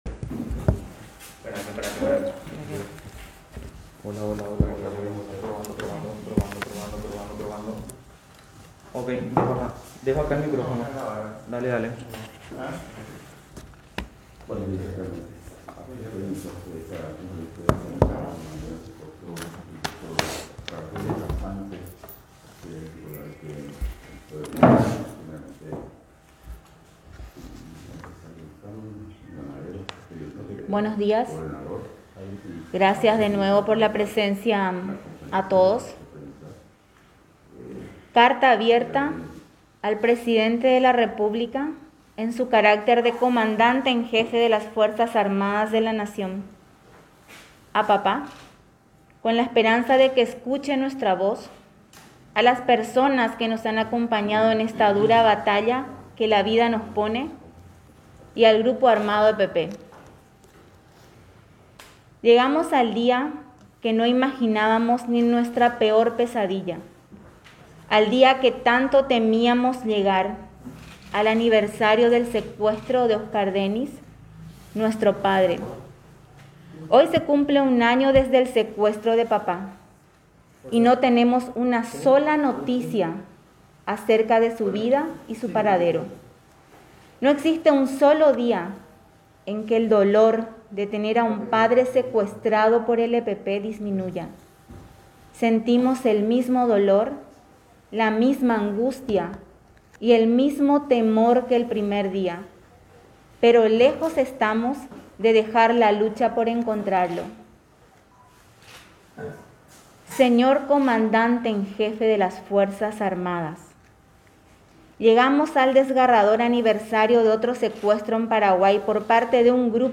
Las hijas realizaron una conferencia de prensa en recordación de esa jornada, criticando al Gobierno por los nulos resultados en la investigación y desafiando al grupo criminal.